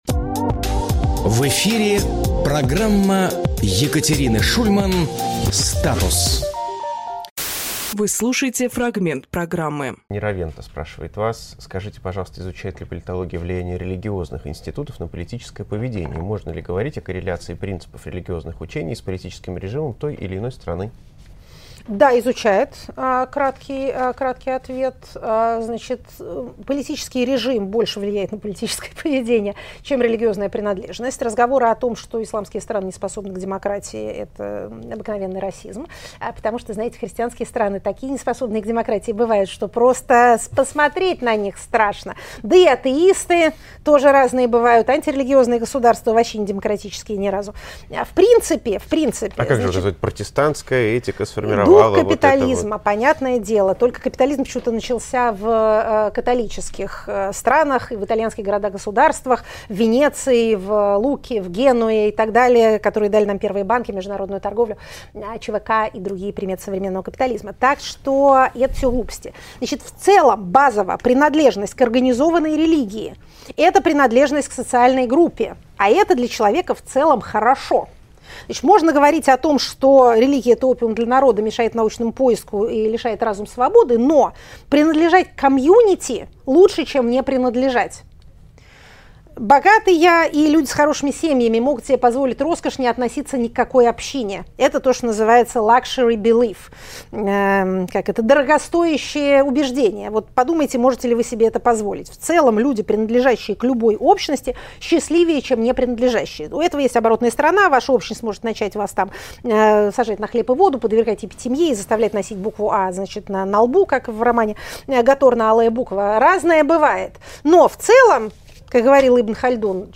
Фрагмент эфира от 01.07.25